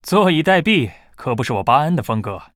文件 文件历史 文件用途 全域文件用途 Bhan_fw_04.ogg （Ogg Vorbis声音文件，长度2.6秒，103 kbps，文件大小：32 KB） 源地址:地下城与勇士游戏语音 文件历史 点击某个日期/时间查看对应时刻的文件。